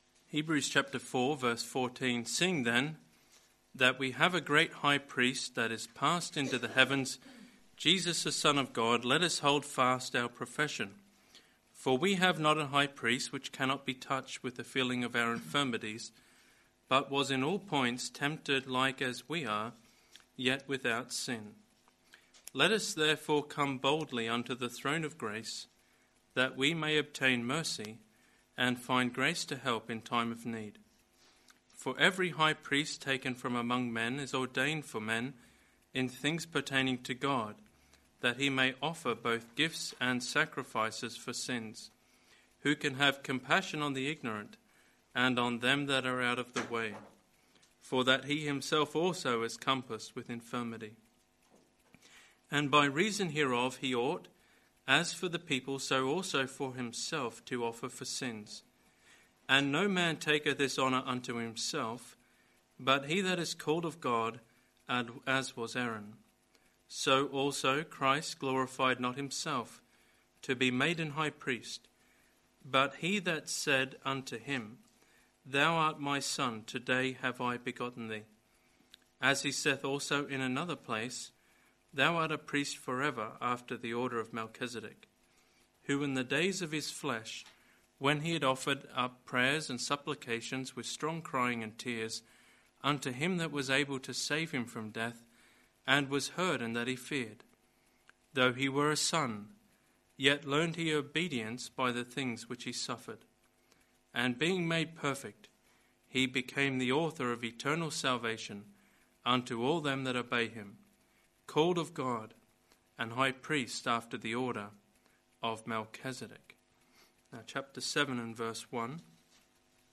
This message was preached at Osborne Road Gospel Hall, Northampton.